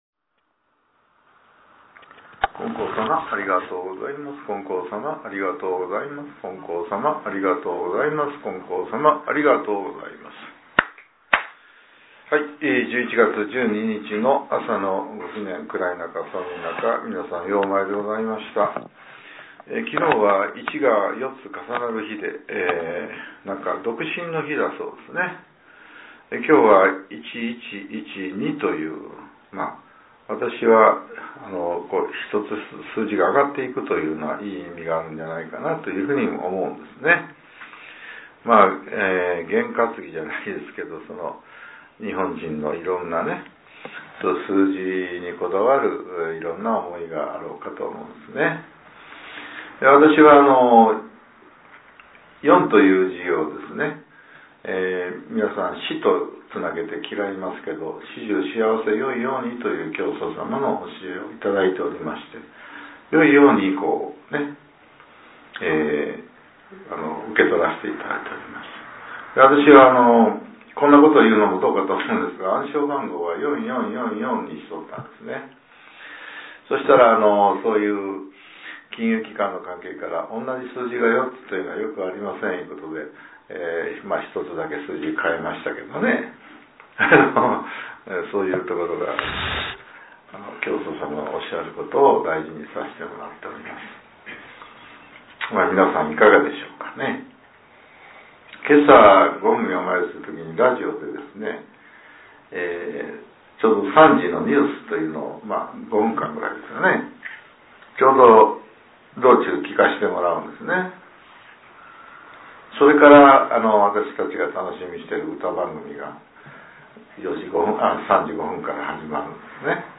令和７年１１月１２日（朝）のお話が、音声ブログとして更新させれています。